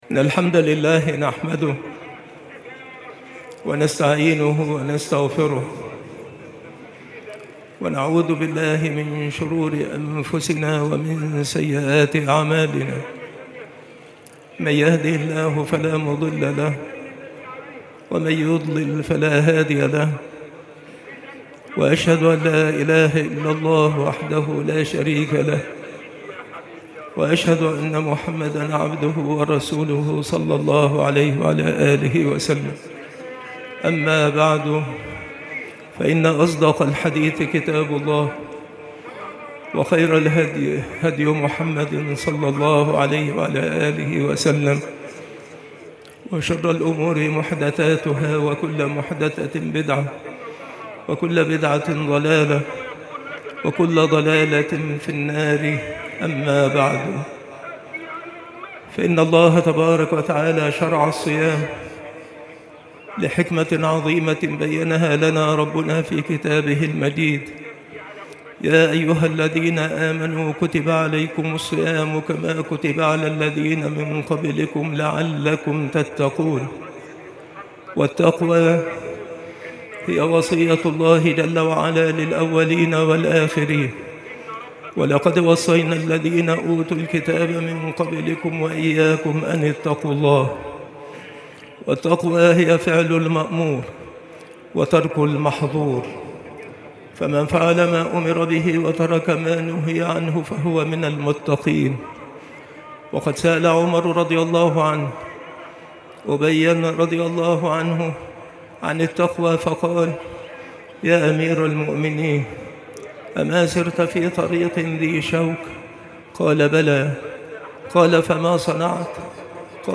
خطبة عيد الفطر لعام 1446هـ
خطب العيدين
بالمسجد الشرقي - سبك الأحد - أشمون - محافظة المنوفية - مصر